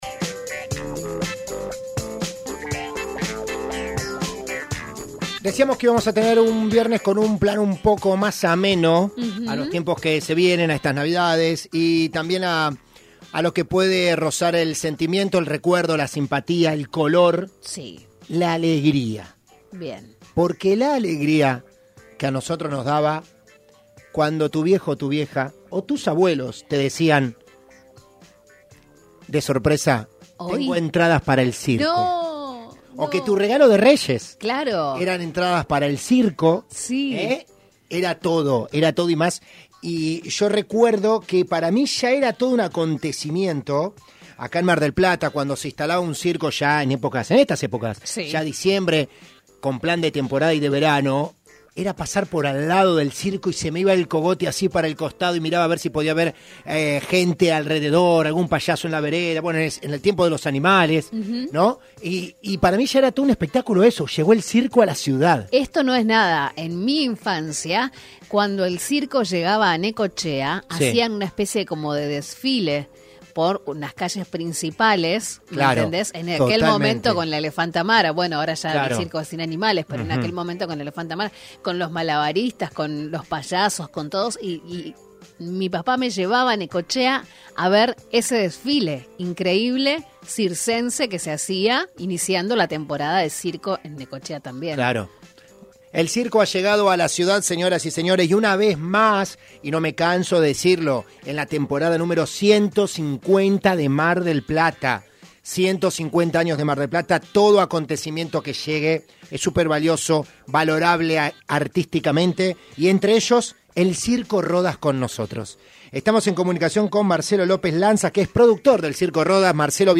Mitre Mar del Plata (FM 103.7)